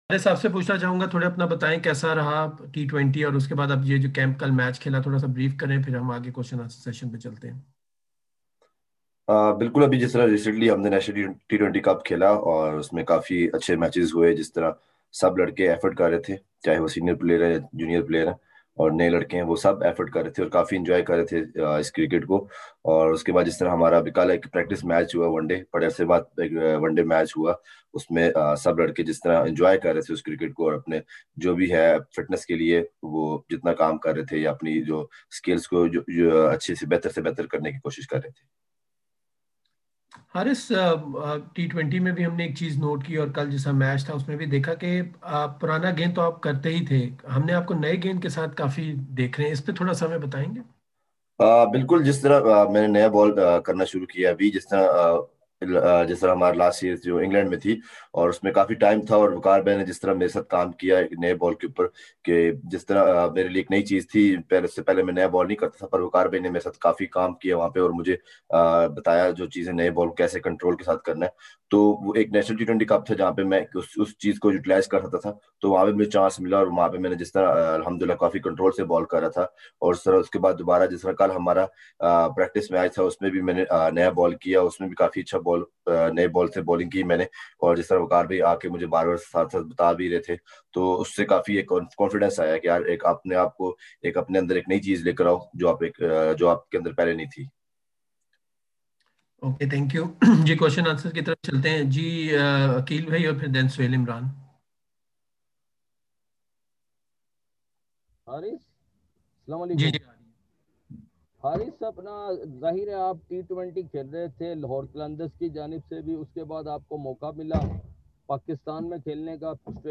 Haris Rauf held a virtual media conference with the local media